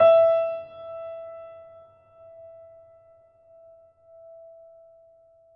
piano-sounds-dev
Steinway_Grand
e4.mp3